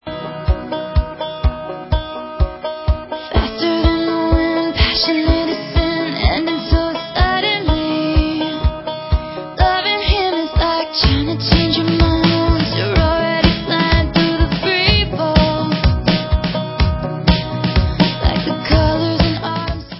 Original Demo Recording